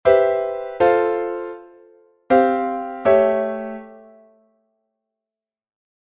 Bei I löst sich die Dissonanz des C-Dur- und E-Moll-Akkords in den F-Dur-Akkord, bei II bewegt sich die vorgehaltene Quarte f zwar nach der Terz e, gleichzeitig schreiten aber die anderen Stimmen zum A-Dur-Septimakkord fort.